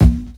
KICK_GC.wav